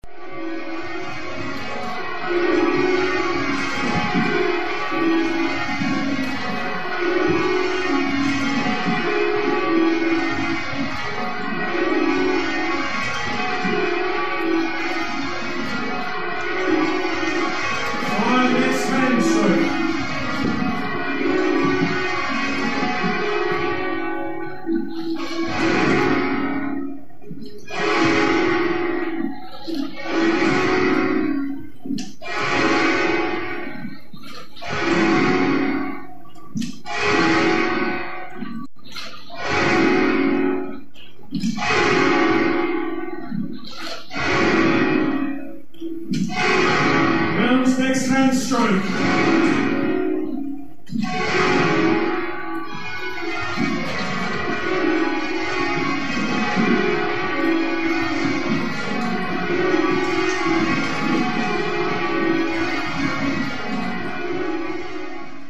2) Firing ie from ringing for example rounds, then all striking simultaneously, then back to the previous sequence.
Worcester Cathedral